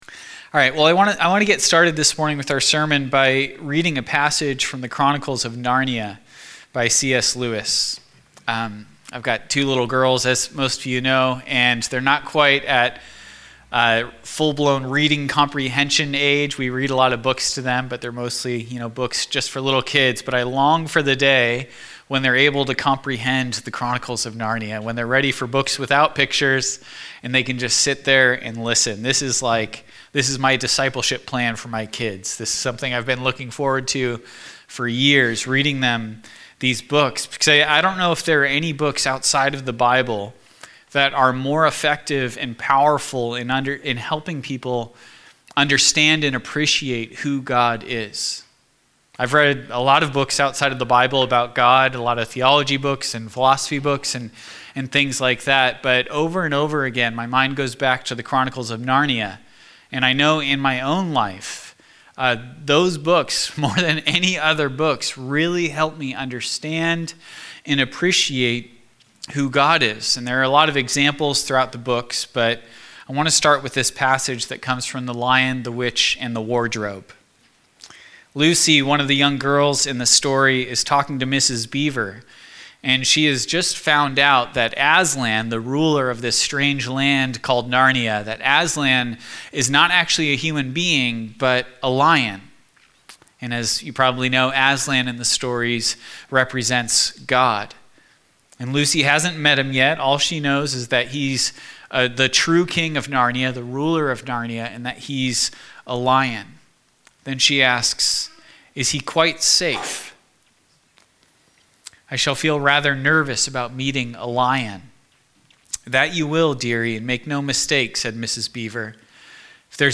Malachi 2 Sermon.mp3